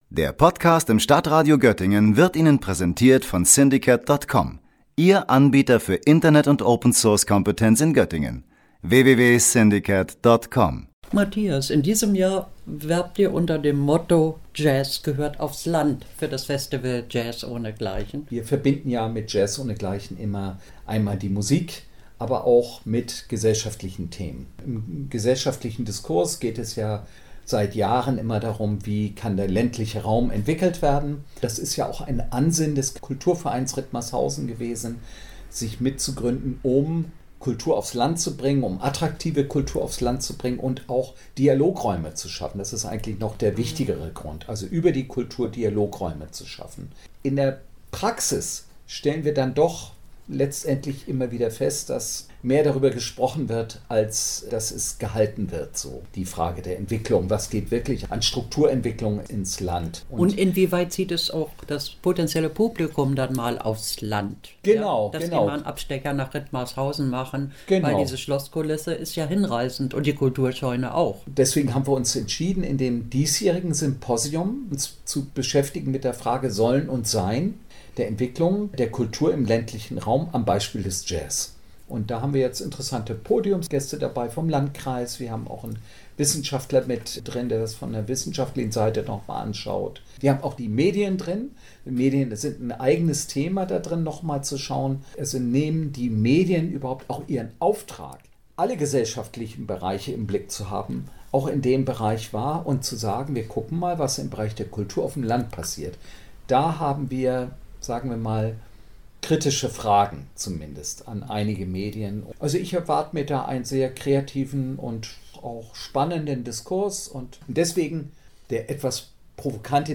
Beiträge > „JazzohneGleichen“ Festival - Gespräch